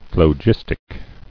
[phlo·gis·tic]